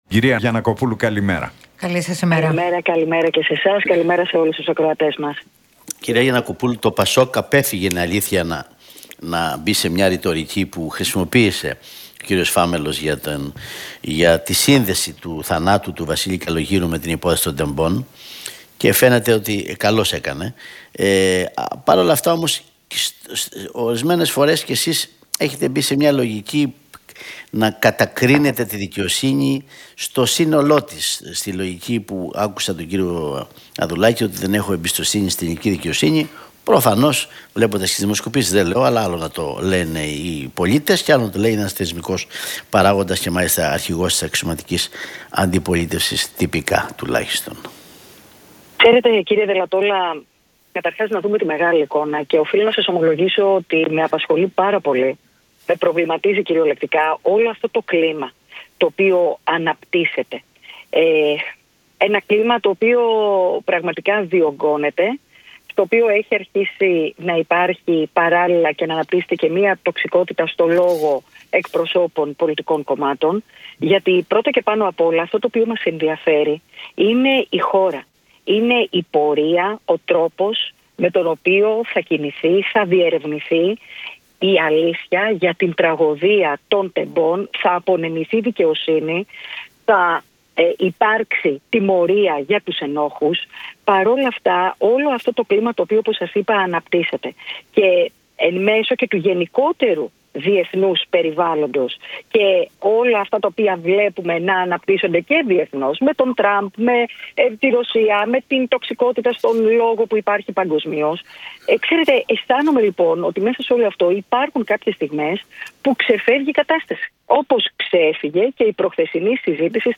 από την συχνότητα του Realfm 97,8